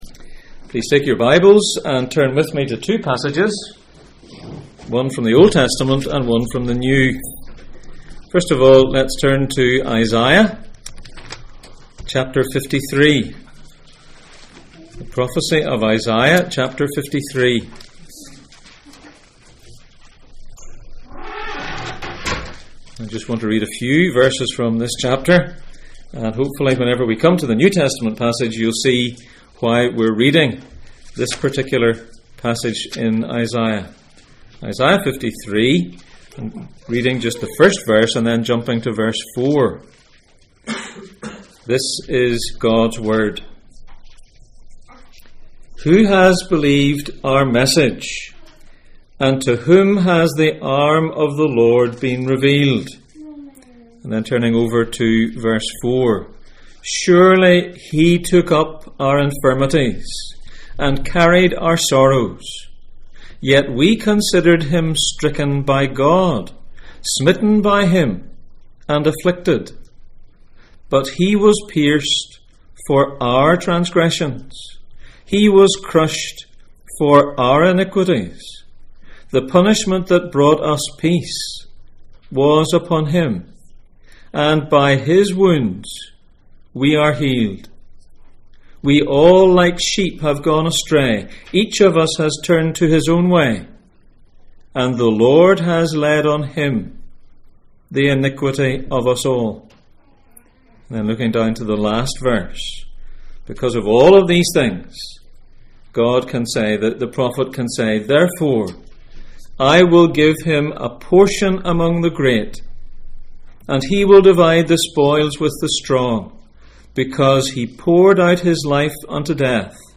Luke 22:36-44 Service Type: Sunday Morning %todo_render% « What do you say?